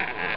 squeek1.sound